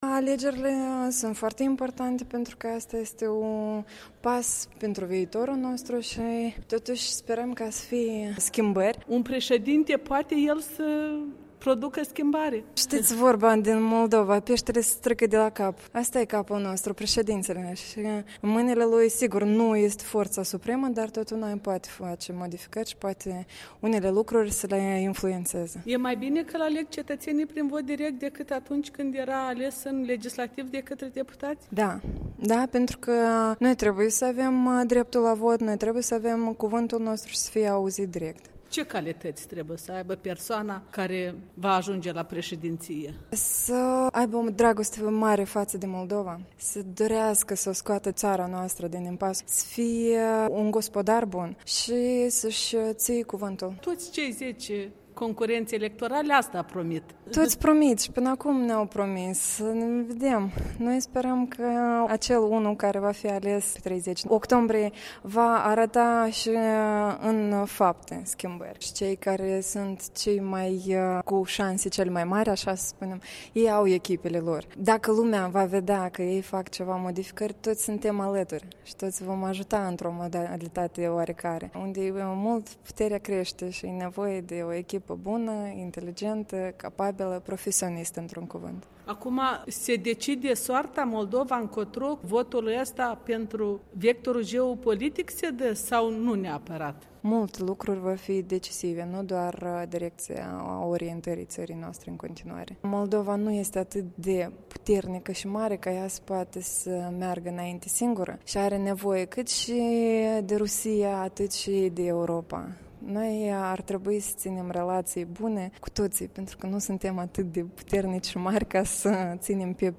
Interviu cu un doctor în științe tehnice din Moldova stabilit în Cehia.